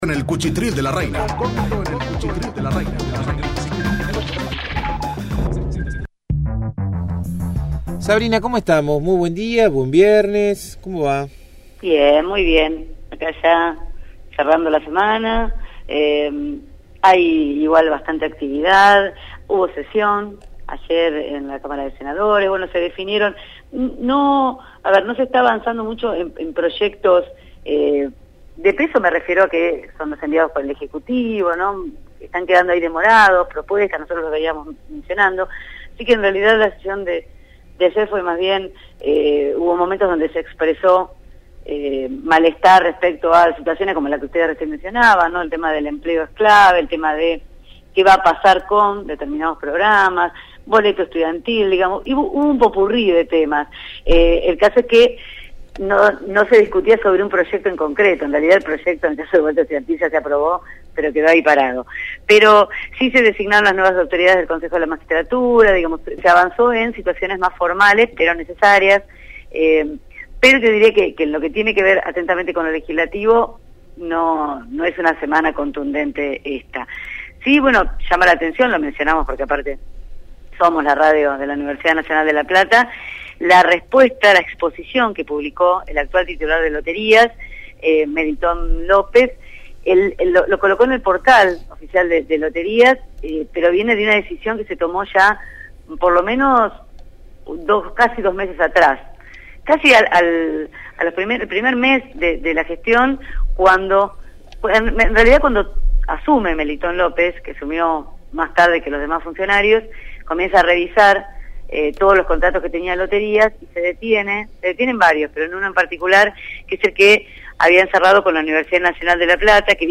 realizó su habitual informe sobre la actualidad política bonaerense. En esta oportunidad se refirió a la suspensión de un convenio entre la Lotería de la Provincia y la Universidad Nacional de La Plata, a instancias del organismo provincial, por considerarlo «sospechoso y oneroso».